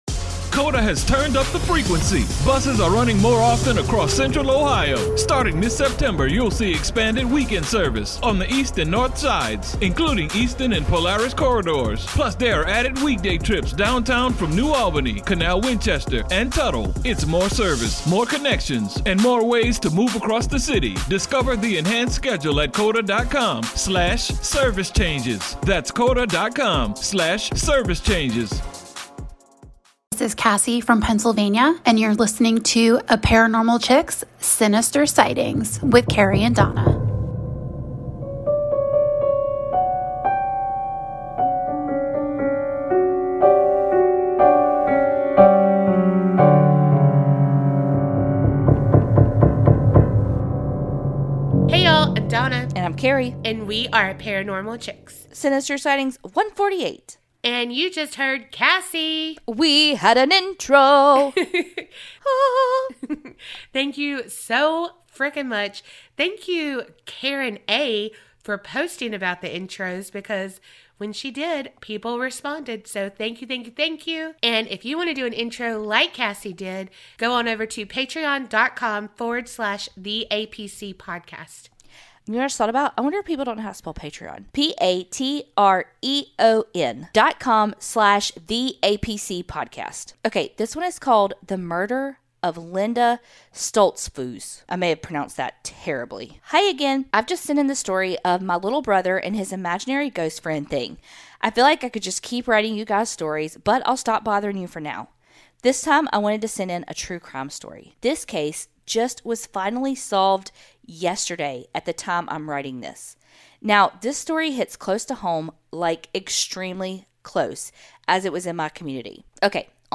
Sinister Sightings are your true crime and true paranormal stories. Every week we read out ones that you've sent in.